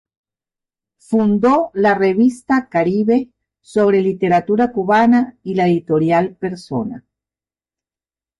Uitgesproken als (IPA)
/editoˈɾjal/